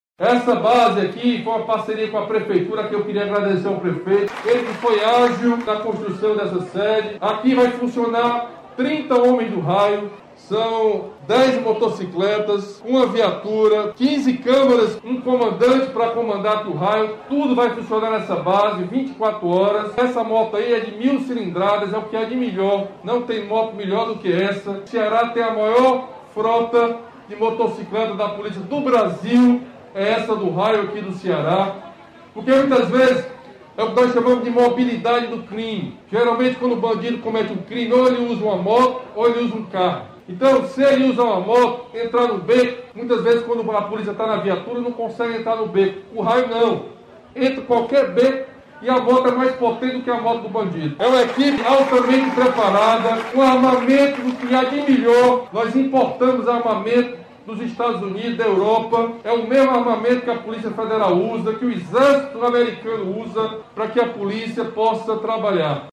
O governador Camilo Santana participou das inaugurações e enalteceu o trabalho realizado pelos policiais cearenses reforçando que o Raio e o sistema de videomonitoramento vão funcionar 24 horas para dar mais segurança à população.